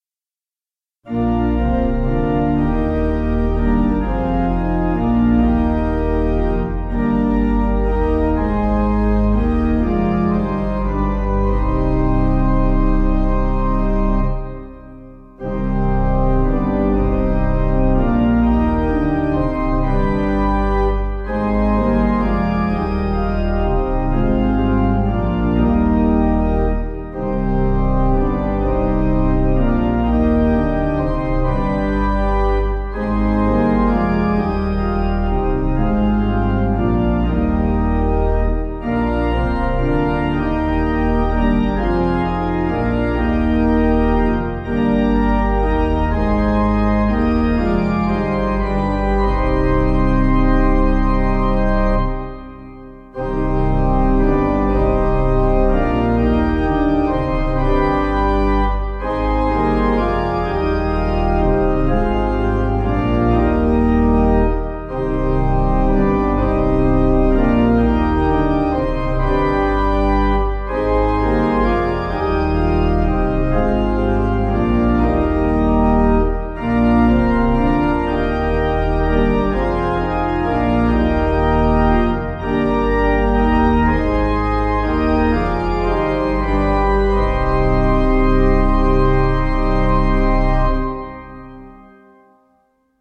Information about the hymn tune CHRISTOPHER (Peter).
Meter: 7.7.7.7.7.7
Key: c minor